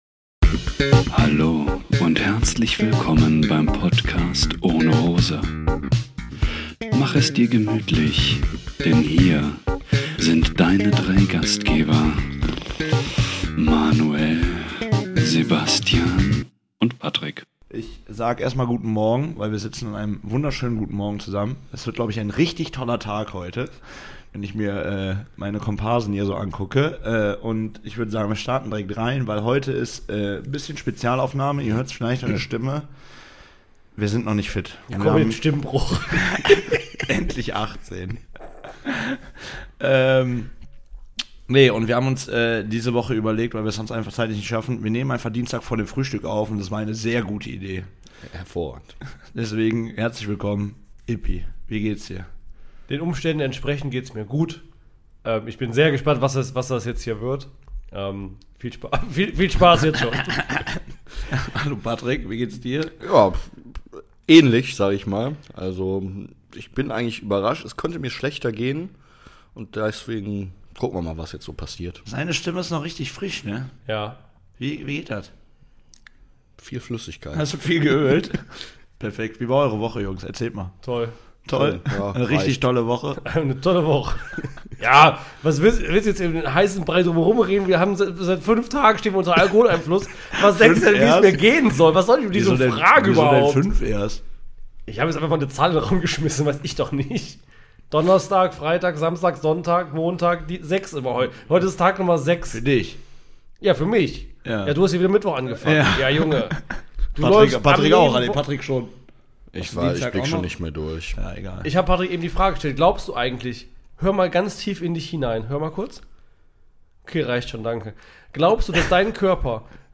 Eine Folge an Schützenfest vor dem Antreten aufnehmen?